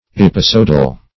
Episodal \Ep`i*so"dal\, a.
episodal.mp3